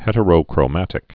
(hĕtə-rō-krō-mătĭk)